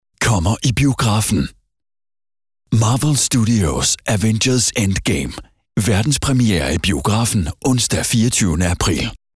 Male
Authoritative, Confident, Cool, Corporate, Engaging, Friendly, Natural, Reassuring, Sarcastic, Smooth, Soft, Warm, Versatile
Microphone: Neumann TLM 102
Audio equipment: pro sound booth